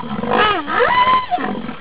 c_rhino_bat2.wav